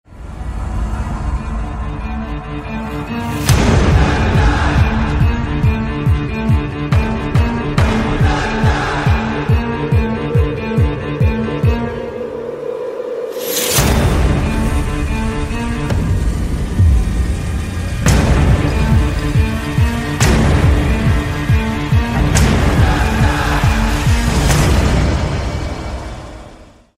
Рингтоны Без Слов » # Рингтоны Из Кино